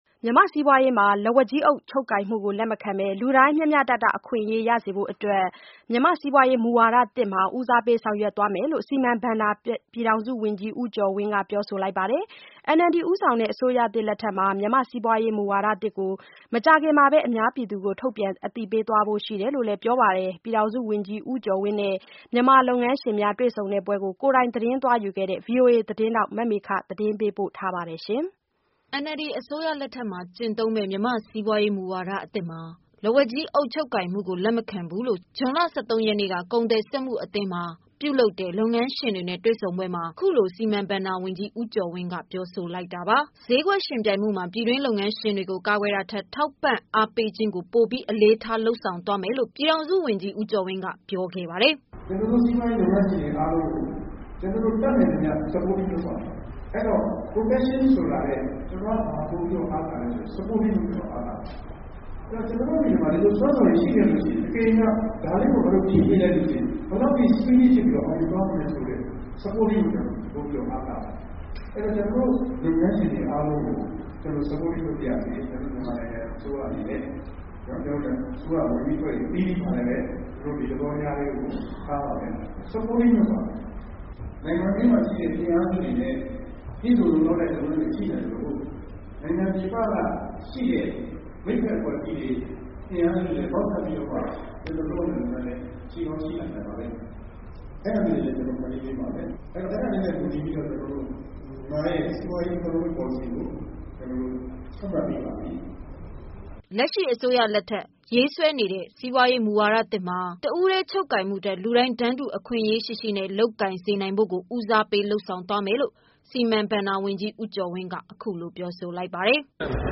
NLD အစိုးရလက်ထက်မှာ ကျင့်သုံးမယ့် မြန်မာ့စီးပွားရေးမူဝါဒအသစ်မှာ လက်ဝါးကြီးအုပ်ချုပ်ကိုင်မှုကို လက်မခံဘူးလို့ဇွန်လ ၁၃ ရက်ကကုန်သည်စက်မုှအသင်းတွင် ပြုလုပ်တဲ့ လုပ်ငန်းရှင်ရှင်များနဲ့တွေ့ဆုံပွဲမှာ အခုလို စီမံ/ဘဏ္ဍာဝန်ကြီး ဦးကျော်ဝင်း ကပြောဆိုလိုက်တာပါ။ ဈေးကွက် ယှဉ်ပြိုင်မှု မှာ ပြည်တွင်း လုပ်ငန်းရှင်တွေကို ကာကွယ်တာထက်၊ ထောက်ပံ့ အားပေးခြင်းကို အလေးပေးဆောင်ရွက်သွားမယ်လို့ ပြည်ထောင်စုဝန်ကြီး ဦးကျော်ဝင်းက ပြောခဲ့ပါတယ်။